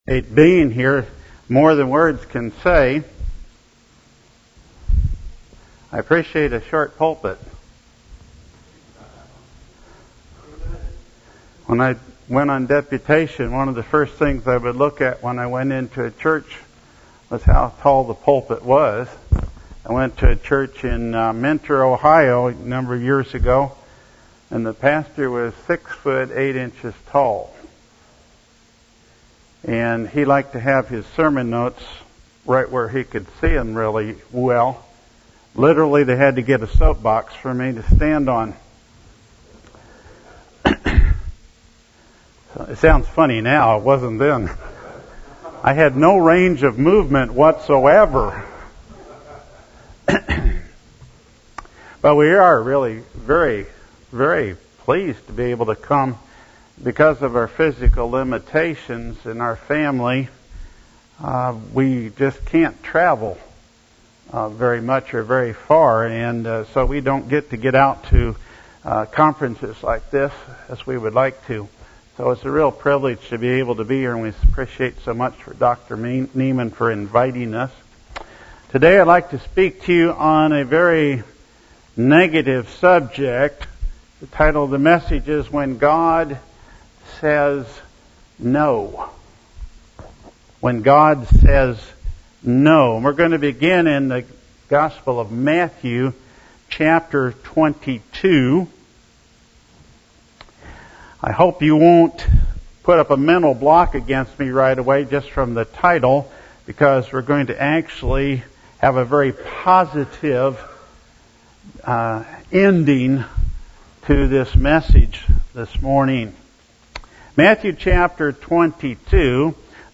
Series: FBFI Northwest Regional Fellowship